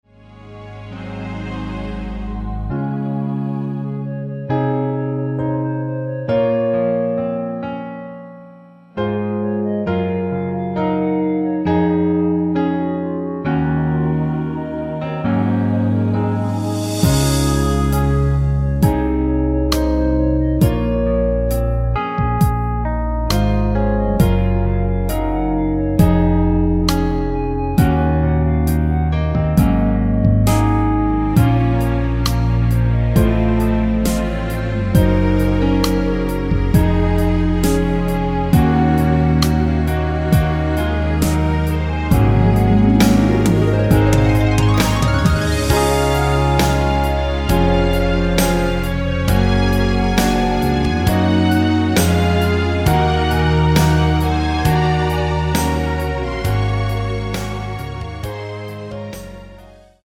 Db
멜로디 MR이라고 합니다.
앞부분30초, 뒷부분30초씩 편집해서 올려 드리고 있습니다.